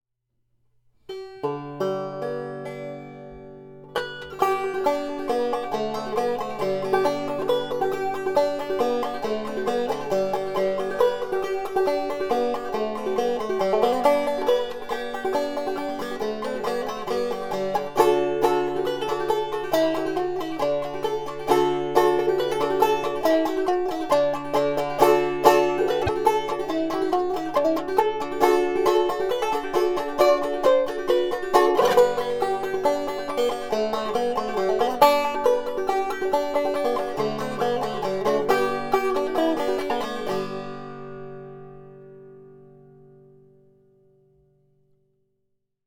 All recordings were done with the aid of a rim tester which allows me to record rims before they are made into a complete banjo.
This rim was overly bright and had an annoying jangling sounds in the treble notes.
CONCLUSION: In this case thinner walls cut treble and improved mid tones and bass tones.